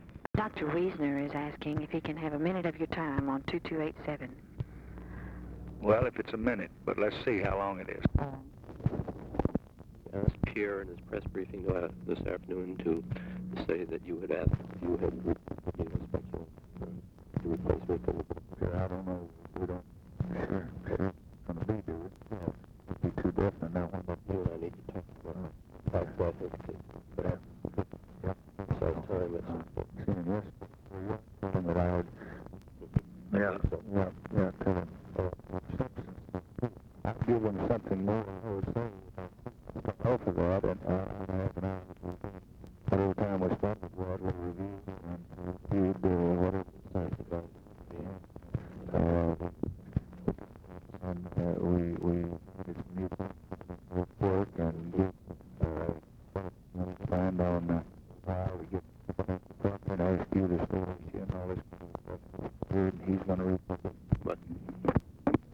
Conversation with JEROME WIESNER, December 11, 1963
Secret White House Tapes